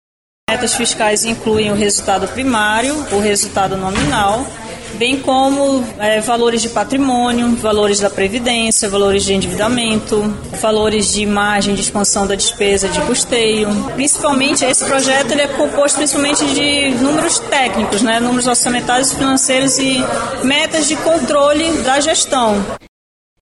A subsecretária Municipal de Finanças, Karliley Capucho, apresentou as metas fiscais da Prefeitura de Manaus.
Sonora-Karliley-Capucho-subsecretaria-da-Semef.mp3